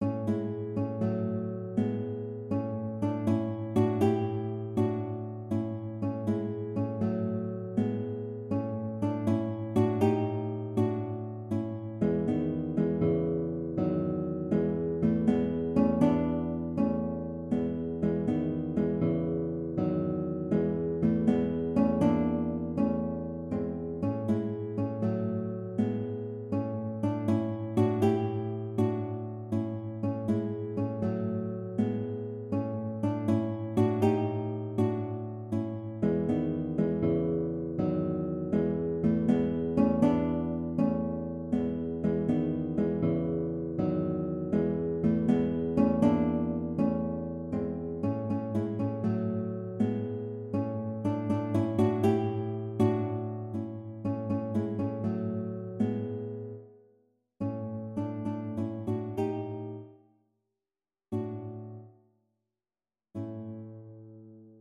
A major (Sounding Pitch) (View more A major Music for Guitar )
6/8 (View more 6/8 Music)
E3-G5
Guitar  (View more Intermediate Guitar Music)
Classical (View more Classical Guitar Music)